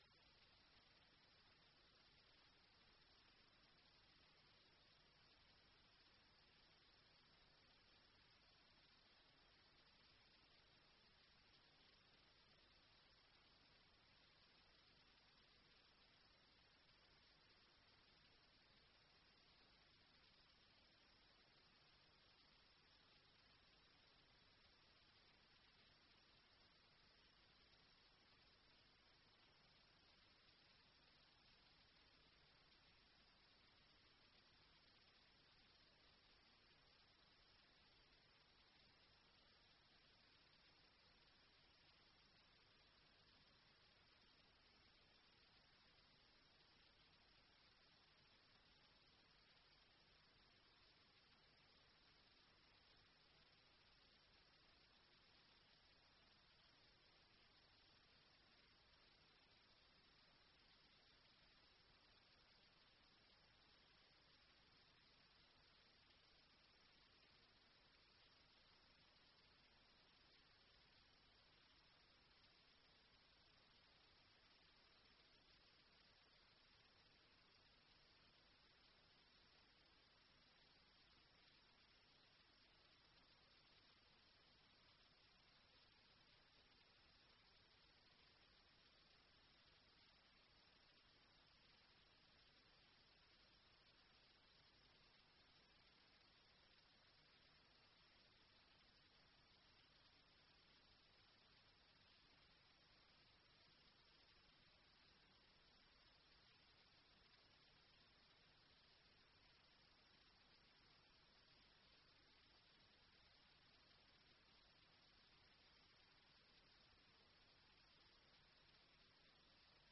Committee Audit Committee Meeting Date 26-06-24 Start Time 6.30pm End Time 7.55pm Meeting Venue Coltman VC Room, Town Hall, Burton upon Trent Please be aware that not all Council meetings are live streamed.
Meeting Recording 240626.mp3 ( MP3 , 18.36MB )